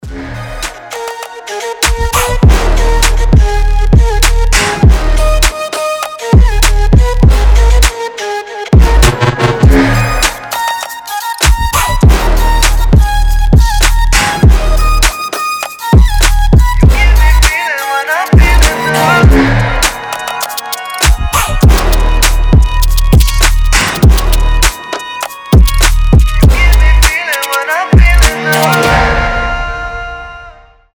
• Качество: 320, Stereo
громкие
мощные басы
качающие